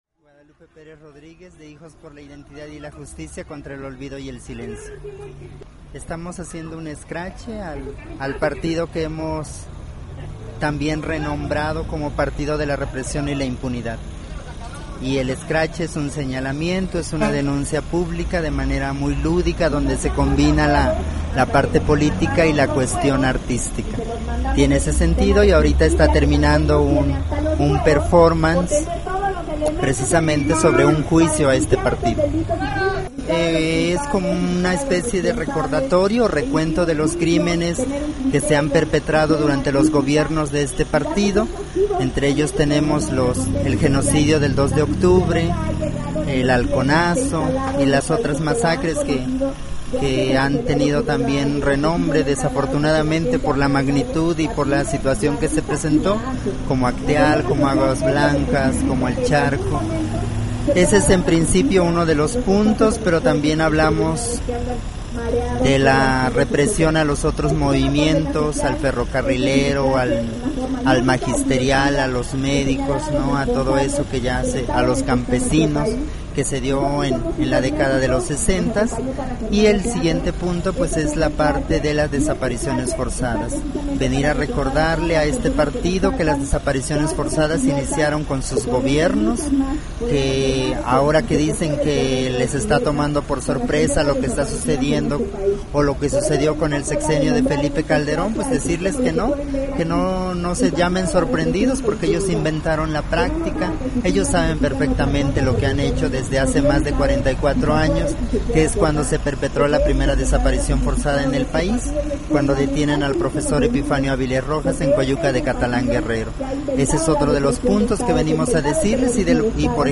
Entrevista_HIJOS.mp3